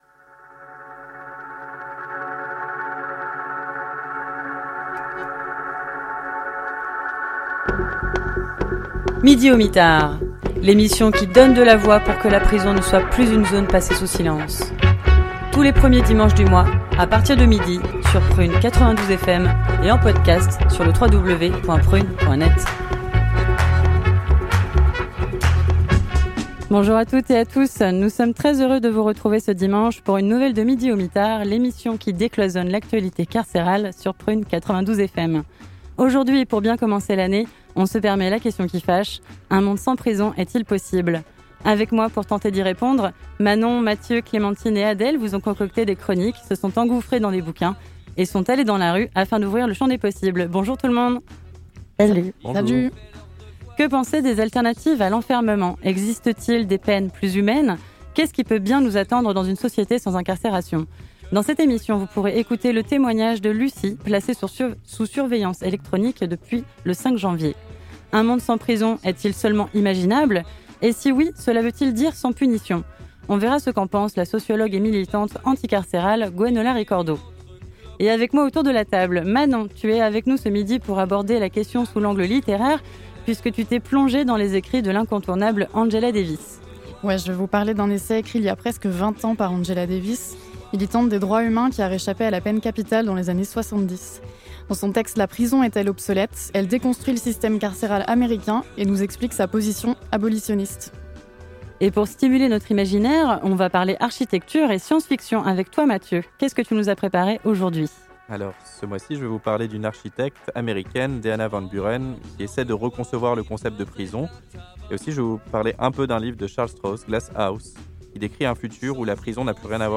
A très bientôt sur les ondes de Prun' 92 FM !